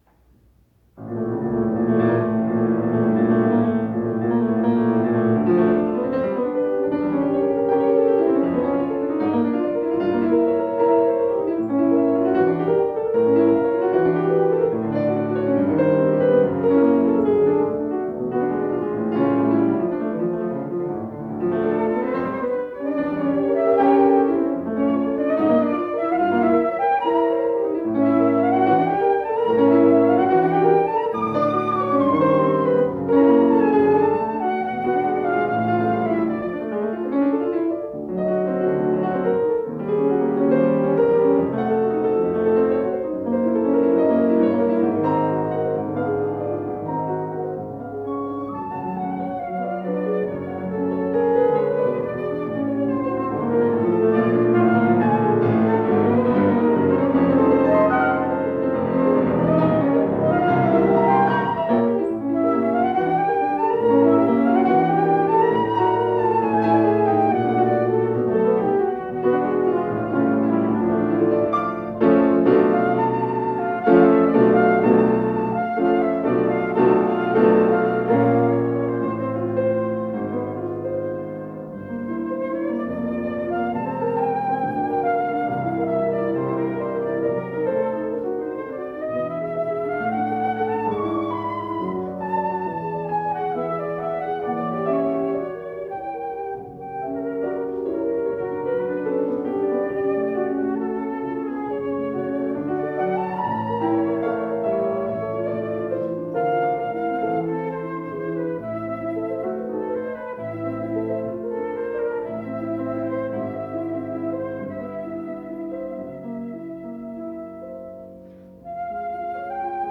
سوناتا الكمان
Cesar_Franck_-_Sonata_in_A,_2nd_movement.ogg